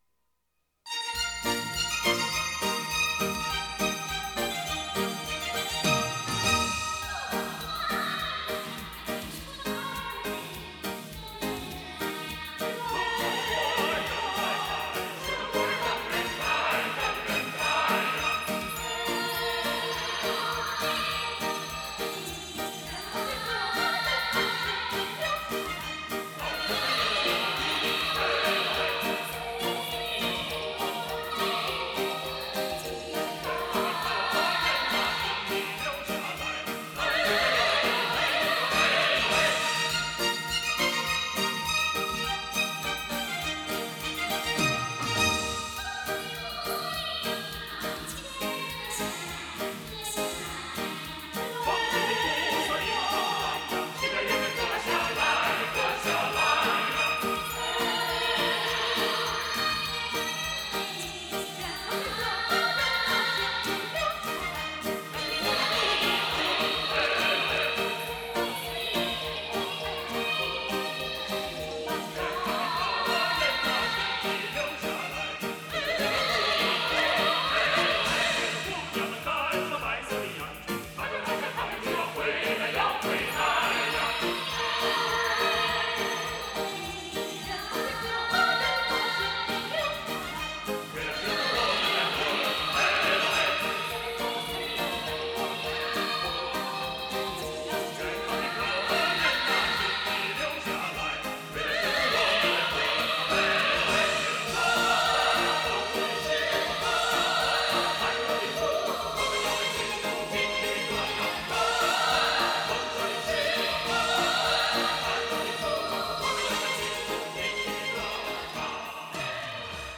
Жанр: Chinese pop / Chinese folk